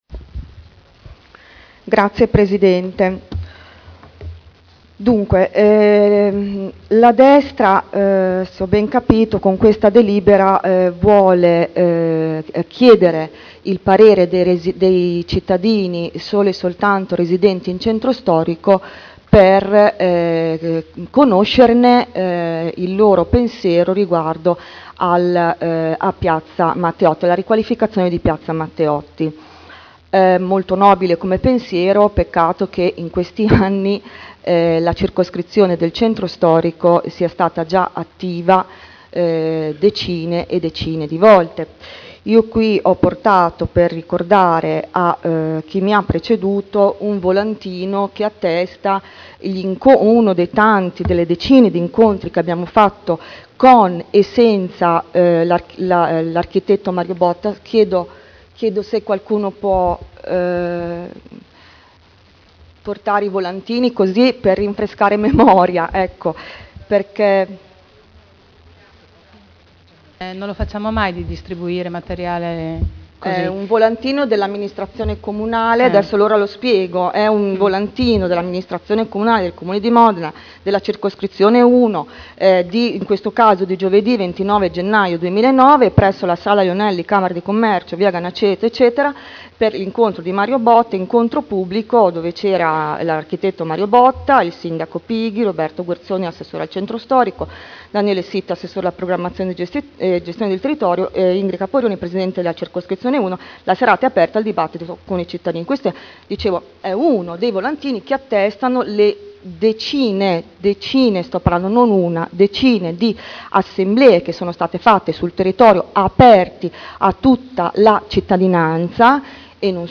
Seduta del 21/07/2011. Dibattito su delibera: Consultazione popolare ai sensi dell’art. 8 degli istituti di partecipazione del Comune di Modena sul progetto di ristrutturazione di Piazza Matteotti (Conferenza Capigruppo del 27 giugno 2011 e del 4 luglio 2011)